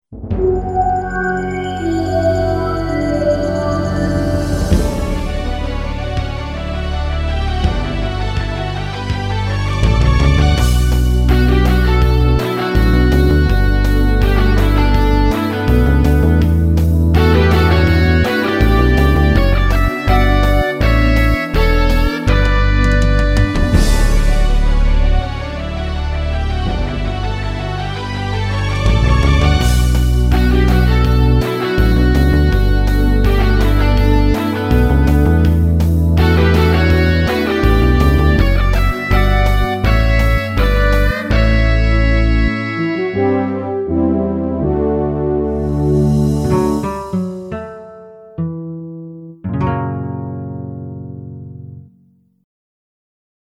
It has a BBC "Radiophonic workshop" sound to it; am I close?